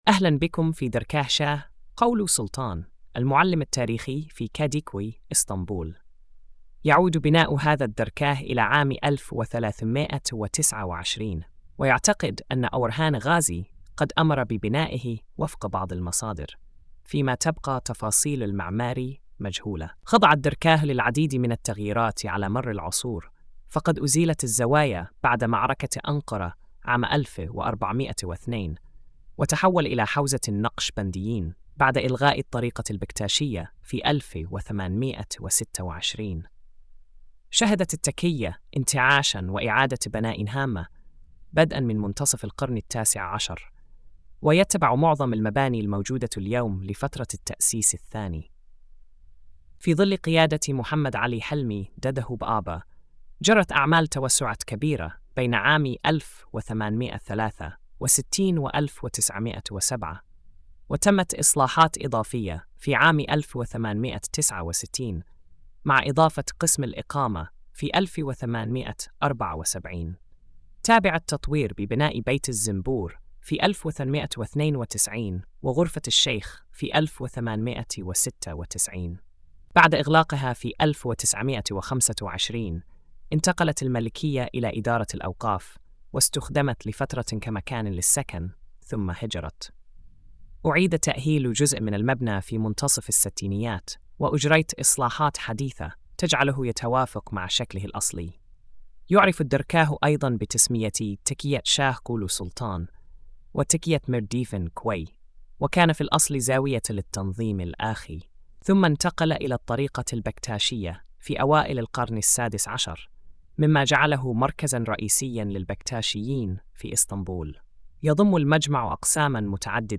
السرد الصوتي: